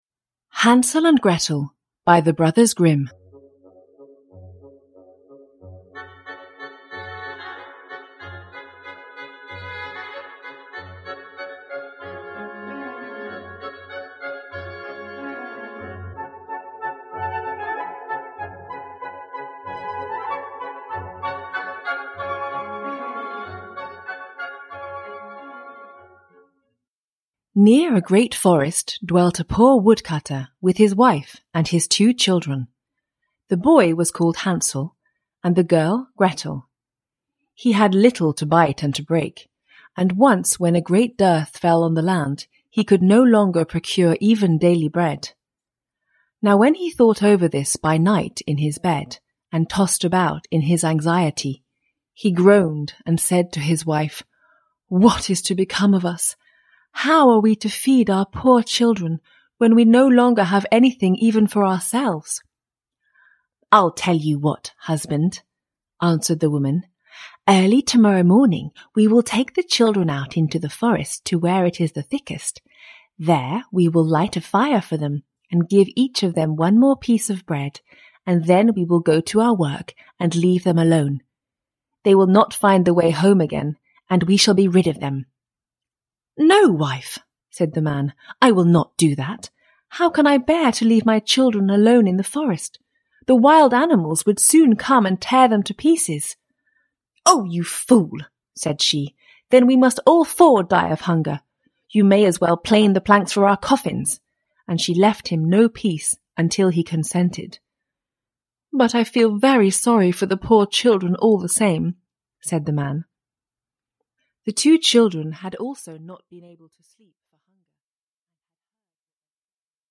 Hansel and Gretel, a Fairy Tale – Ljudbok – Laddas ner